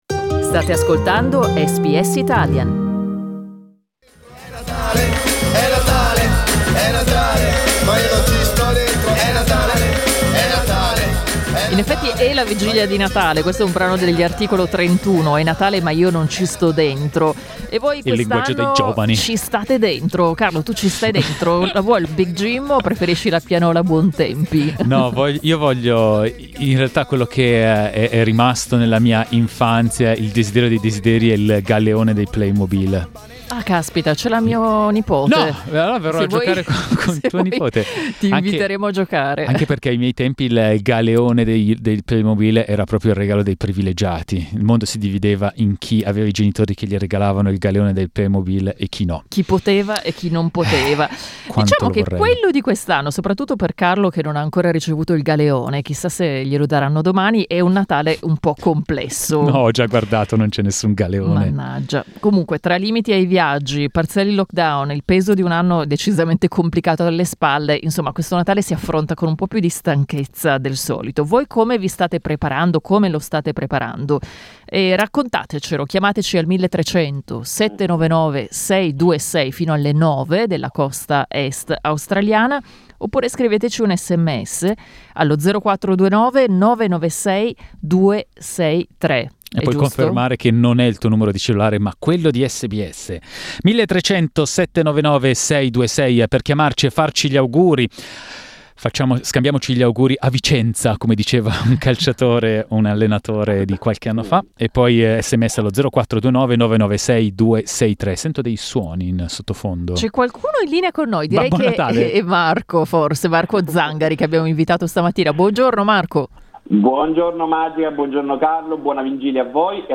Il Natale è letteralmente alle porte: abbiamo aperto le linee per parlare con ascoltatori e ascoltatrici di preparativi e menù, e per scambiare gli auguri di rito.